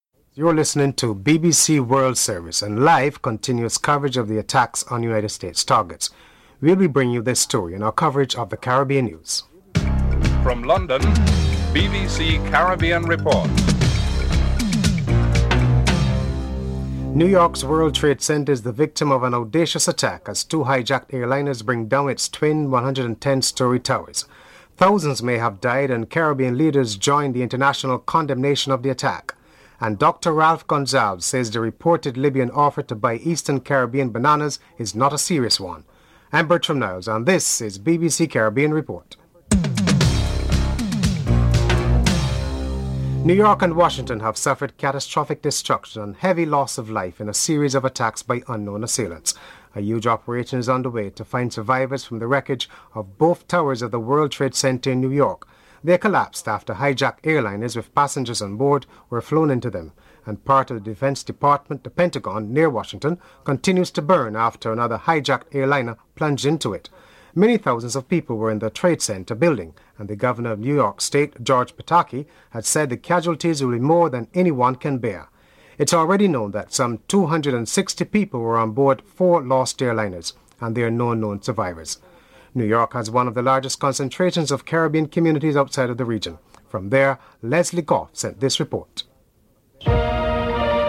Prime Minister Basdeo Panday and Foreign Affairs Minister Mervyn Assam are interviewed.
Prime Minister Ralph Gonsalves is interviewed (09:02-11:31)